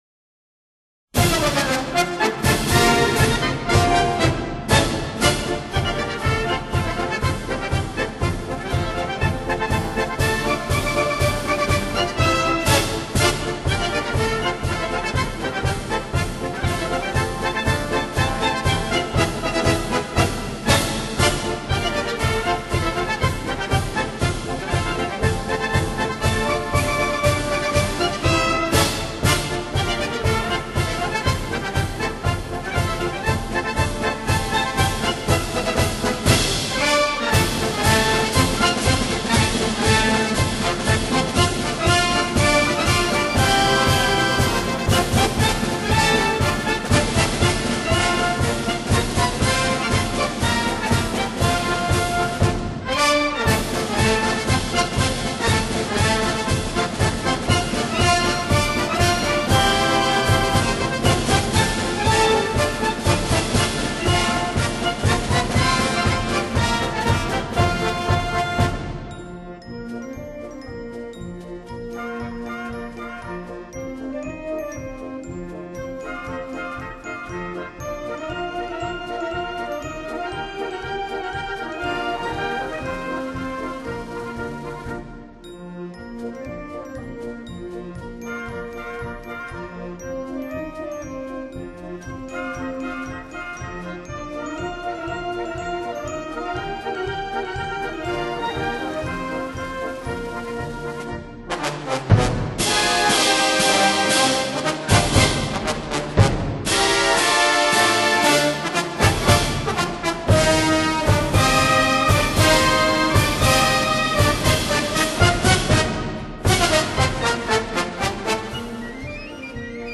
march for band